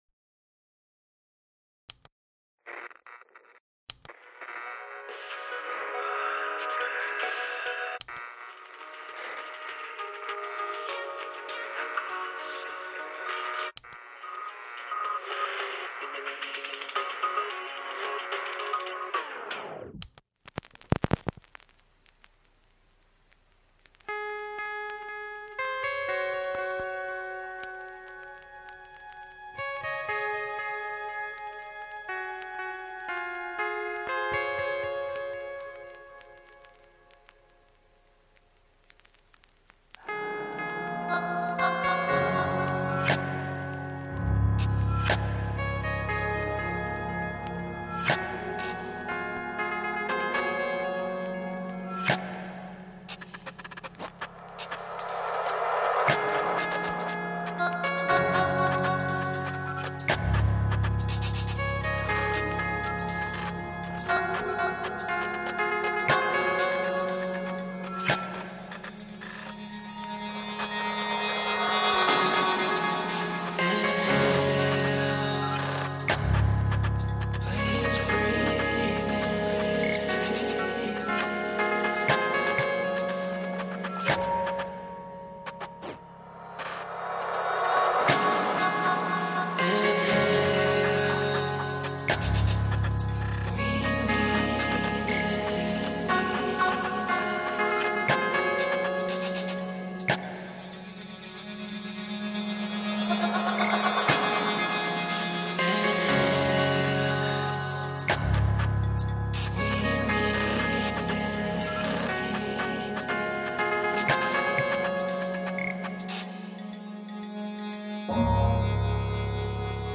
Have some more semi-chilled glitchy guitar music.